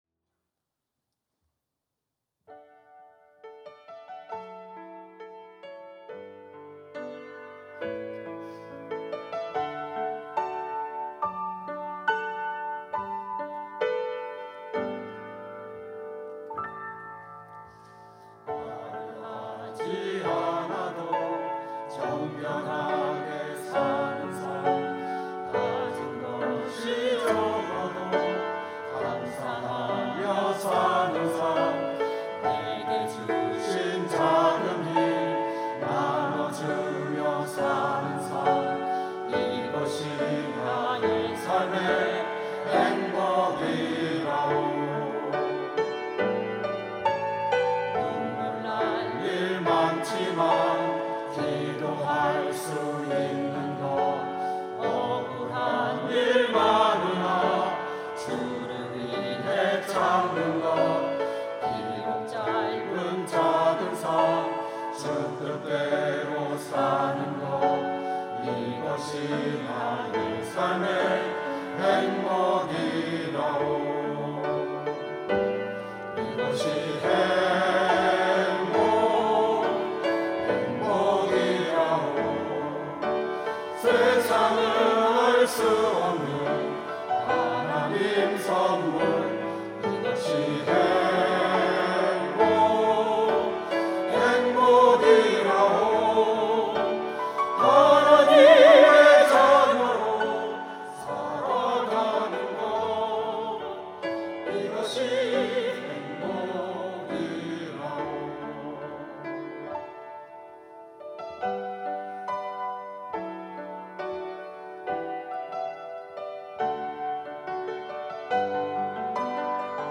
특송과 특주 - 행복
청년부 2022 청년부 교사